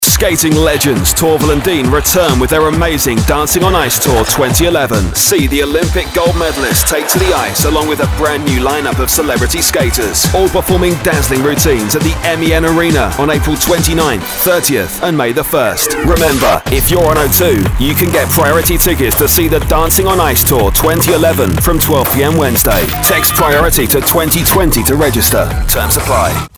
From cool & credible to energetic sports commentator. A hip, young sell for your product.